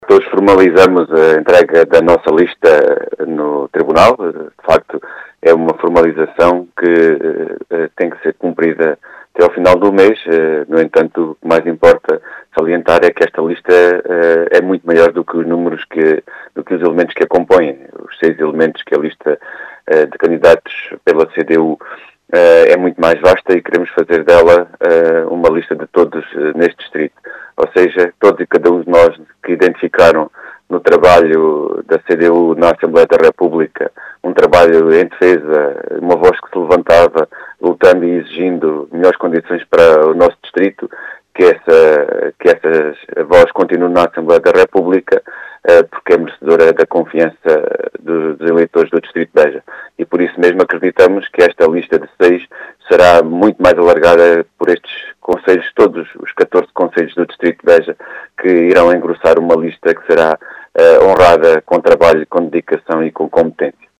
Uma lista que é muito mais vasta, segundo referiu à Rádio Vidigueira, João Dias, o cabeça de lista da CDU, que quer manter a “voz” na Assembleia da República.